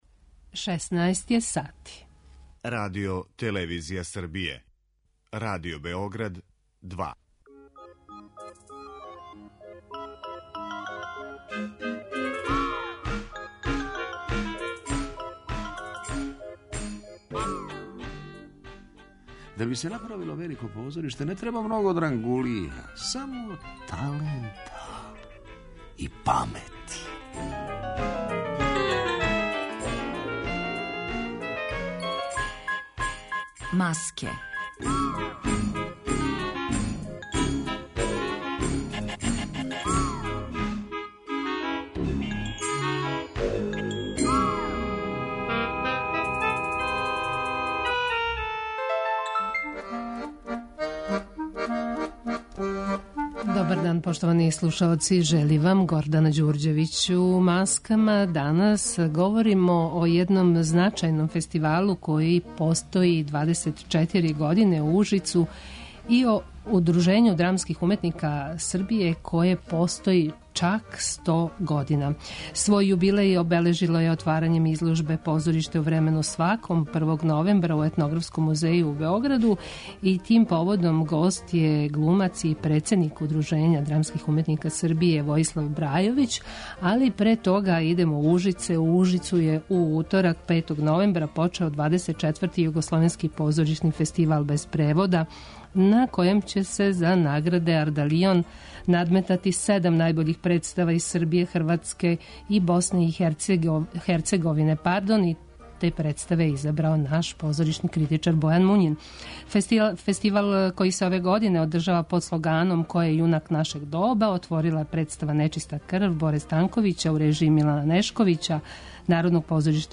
О положају драмских уметника данас разговарамо са глумцем Војиславом Брајовићем, председником Удружења драмских уметника Србије.